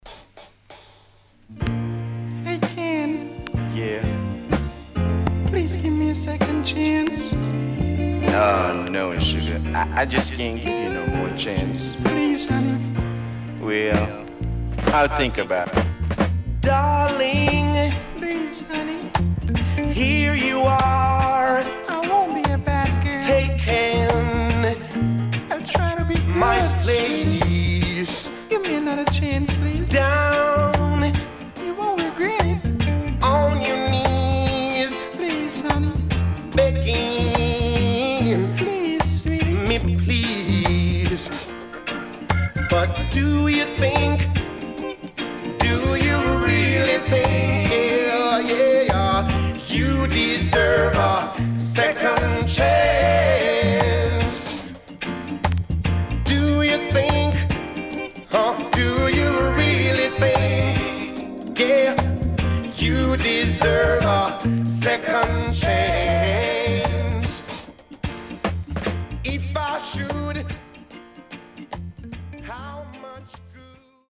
HOME > Back Order [VINTAGE LP]  >  EARLY REGGAE
SIDE A:所々チリノイズ、プチノイズ入ります。